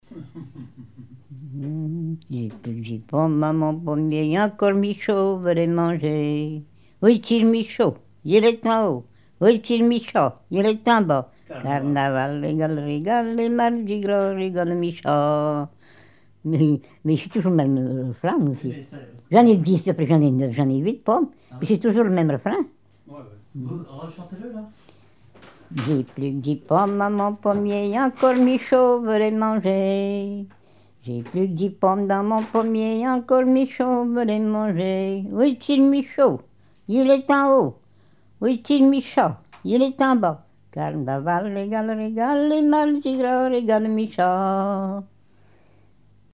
ronde : grand'danse
Genre énumérative
Pièce musicale inédite